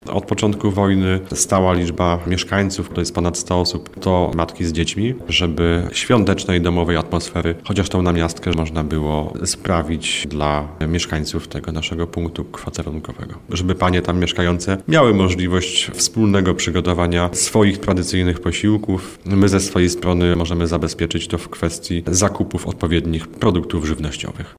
Będą one obchodzić święta Zmartwychwstania Pańskiego zarówno w obrządku katolickim, jak i tydzień później w obrządku prawosławnym – mówi wicestarosta biłgorajski Tomasz Rogala.